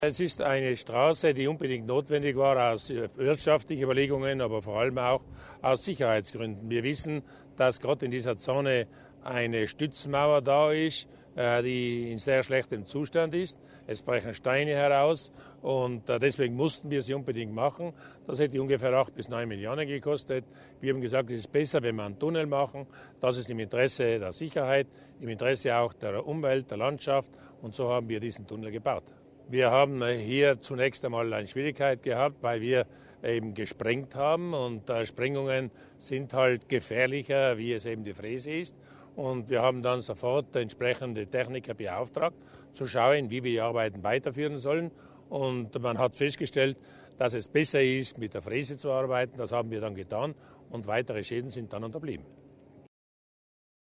Landeshauptmann Durnwalder zu den Bemühungen des Landes in Sachen Straßenbau
LPA - Die offizielle Feier zur Eröffnung der Umfahrung von Sonnenburg mit den Bürgern und Gemeindevertretern ging heute, 28. Dezember, in Sonnenburg über die Bühne.